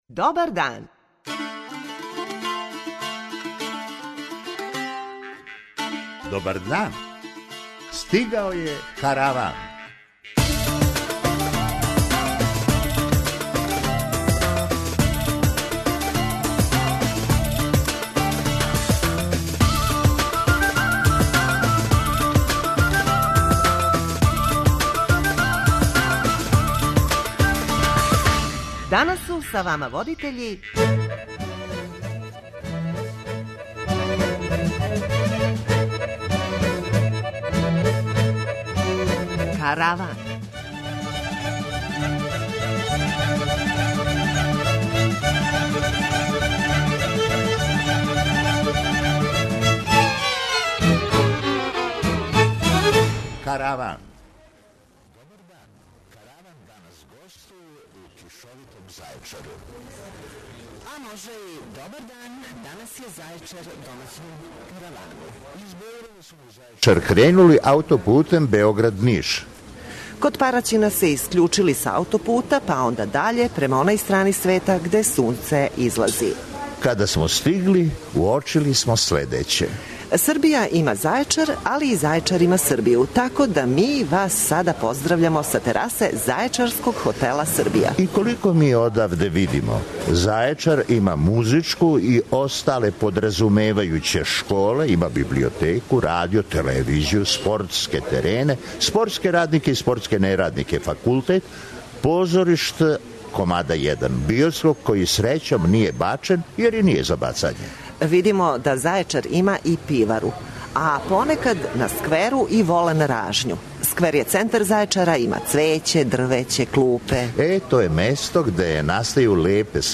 Ово је позив за вас, дођите на чашицу разговора, ми смо на тераси зајечарског хотела Србија.
преузми : 23.52 MB Караван Autor: Забавна редакција Радио Бeограда 1 Караван се креће ка својој дестинацији већ више од 50 година, увек добро натоварен актуелним хумором и изворним народним песмама.